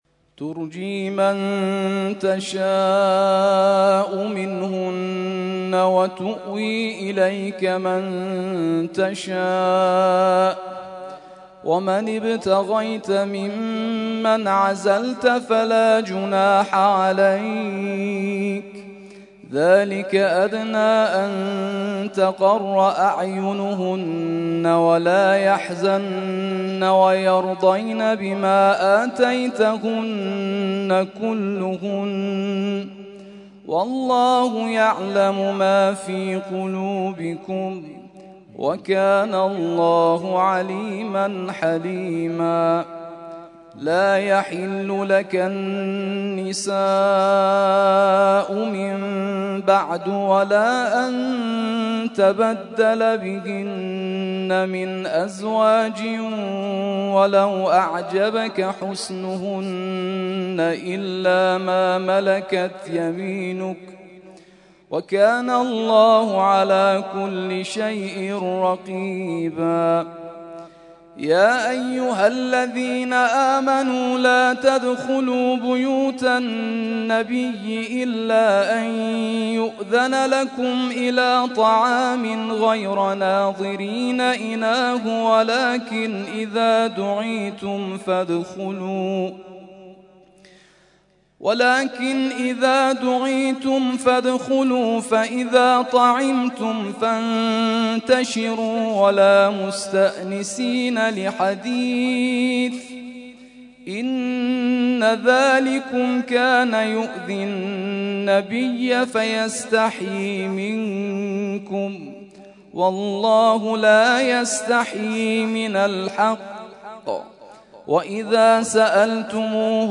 ترتیل خوانی جزء ۲۲ قرآن کریم در سال ۱۳۹۲